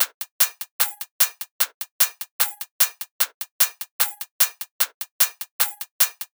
VR_top_loop_supersauce_150.wav